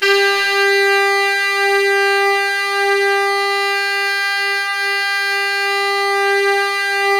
SAX_sfg4x    241.wav